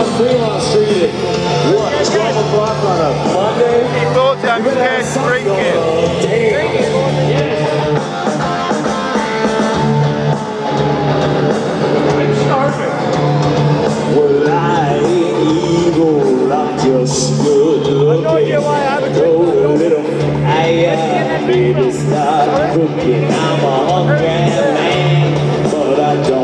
Las Vegas Fremont street